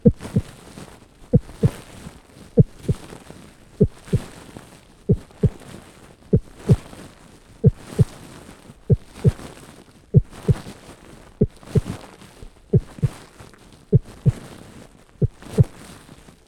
胎内音
むかし聴いたところ、どうやら心音と血流音が主な音で、ほとんどホワイトノイズのような音でした。
heartbeat.mp3